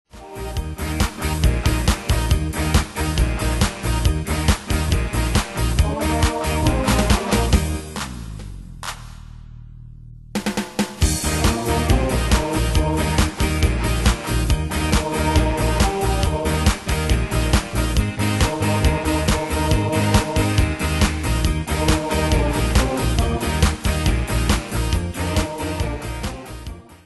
Style: Dance Année/Year: 2003 Tempo: 138 Durée/Time: 3.40
Pro Backing Tracks